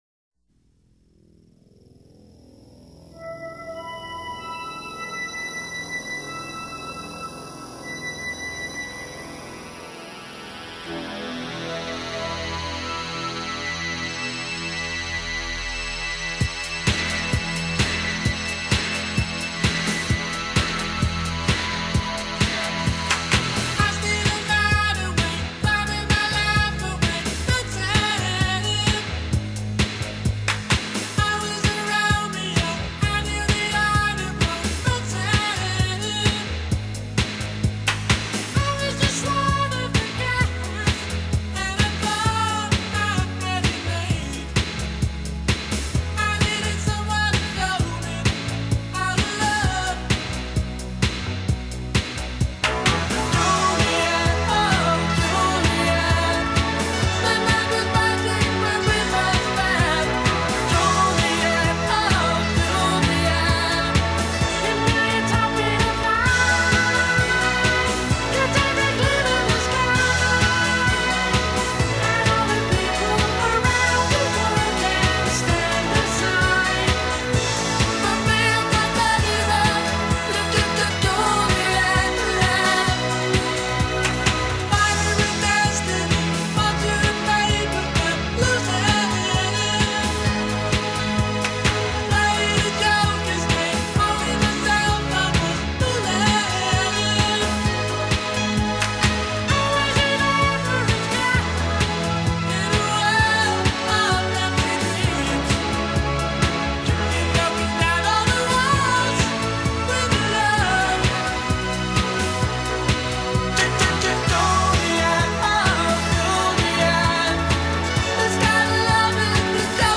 Genre: Pop, Soft Rock